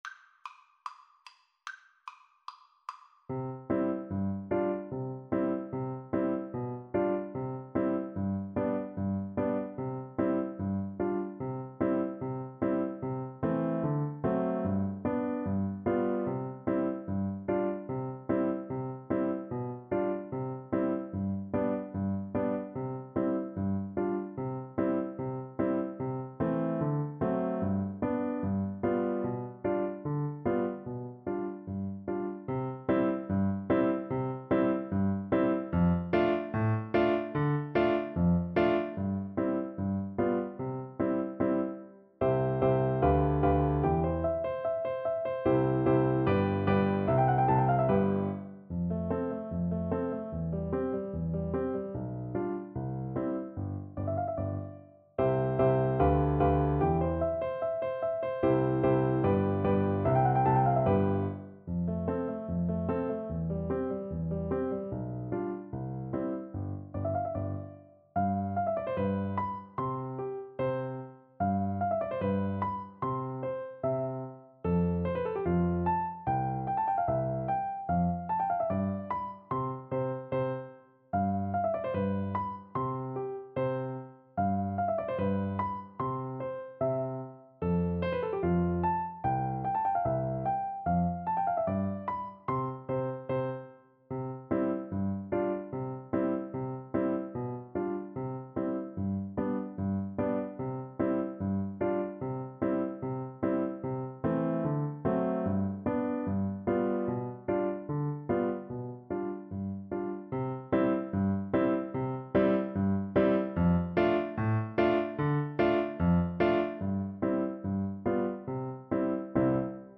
Allegretto = 74
Classical (View more Classical Percussion Music)